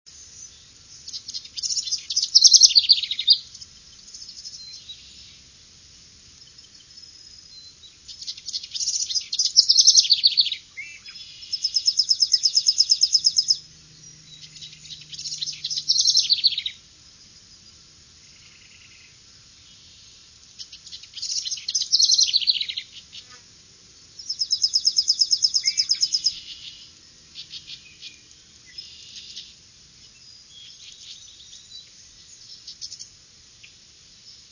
House Wren
House Wren song and calls.
Notice the very low introductory notes and the high note suffix of the first part of the two part song.  Various gurgles, similar in sound to the song prefix can also be heard.  A Chipping Sparrow sings in the background.
wren_house_780.wav